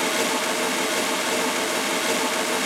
drum-sliderslide.wav